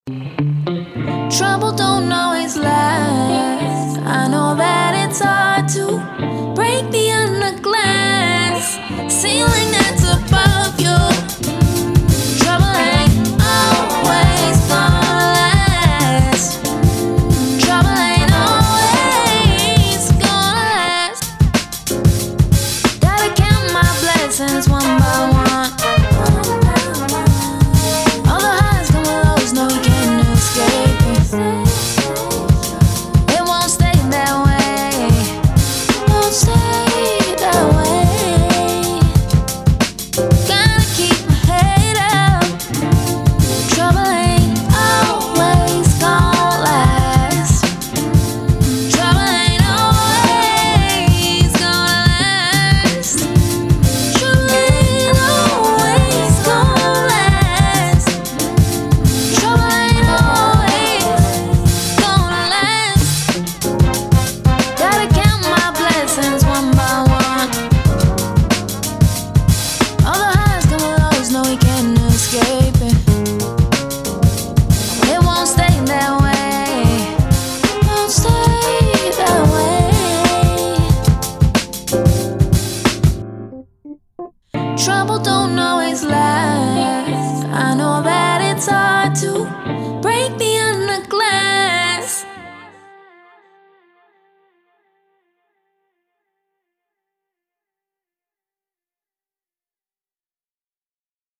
R&B, Soul
F Major